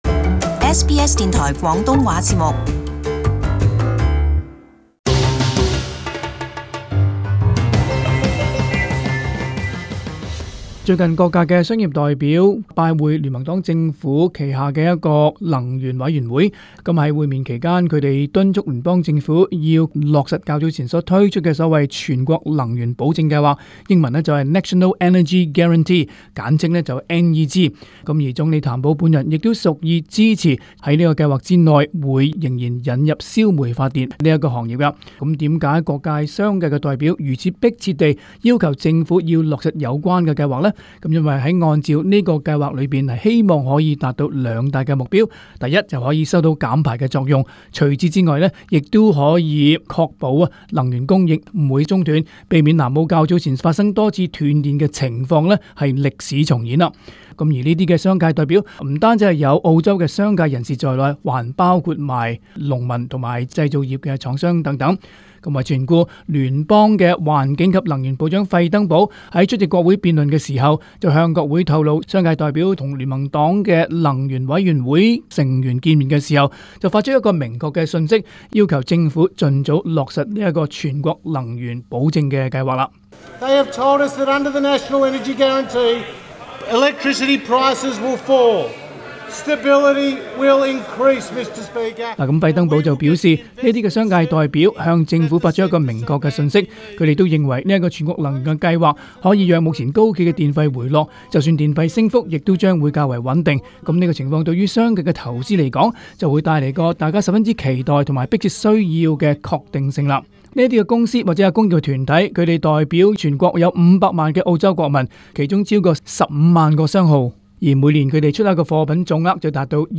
【時事報導】各界敦促政府早日落實全國能源保證計劃